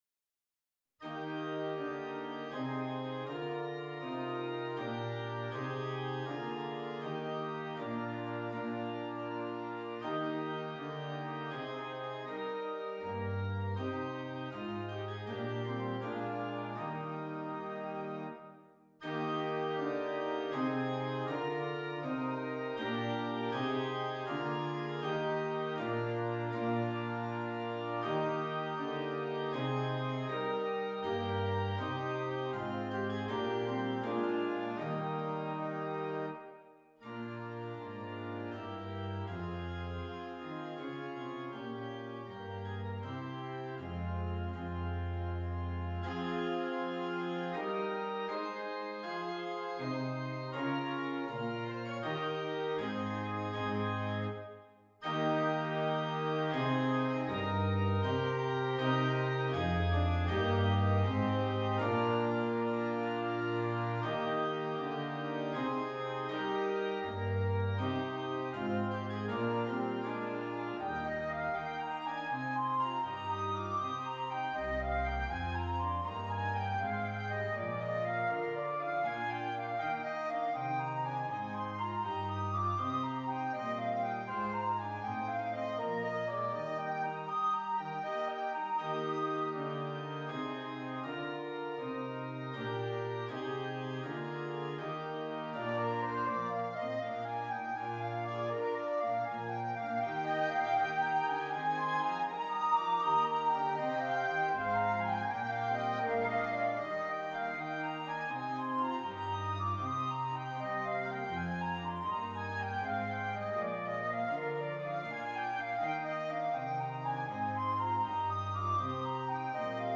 2 Flutes and Keyboard
Difficulty: Easy-Medium Order Code